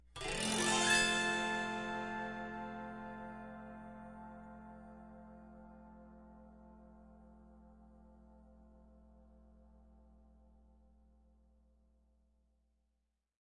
自动竖琴和弦 " A7
描述：立体声录音（24位48k AIFF），用自鸣琴演奏的和弦。录音机。Mbox Mini。麦克风。Dean Markley接触式麦克风（R通道），MXL 993小振膜电容（L通道）。处理。轻微的淡出。
标签： 音响 录音 国家 大弦 弹拨 24位立体声 长期释放 竖琴 仪器 未经处理的 串仪器 竖琴 民俗 单弦 古筝 样品 兰草 AIFF 竖琴 多和弦 48K 和弦
声道立体声